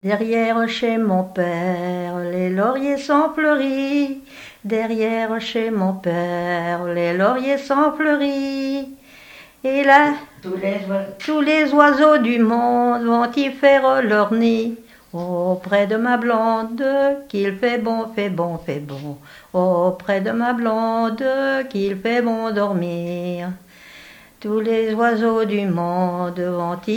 Genre laisse
témoignage sur les noces et chansons traditionnelles
Pièce musicale inédite